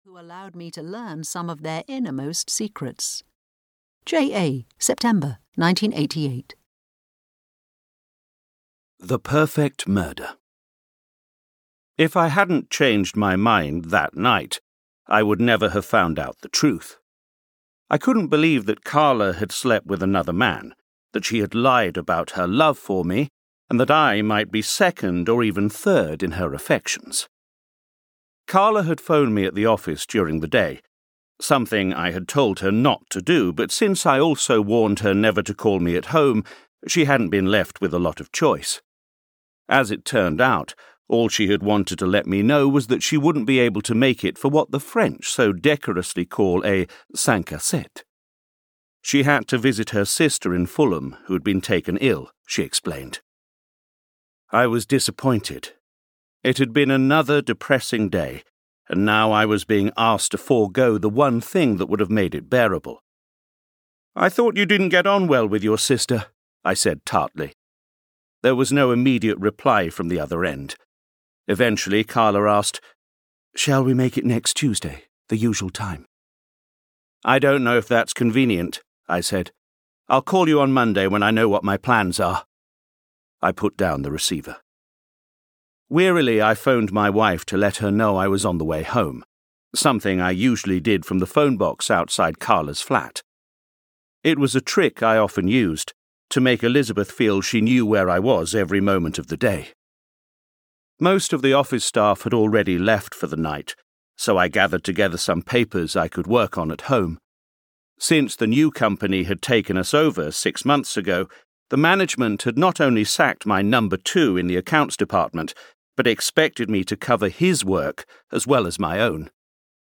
A Twist in the Tale (EN) audiokniha
Ukázka z knihy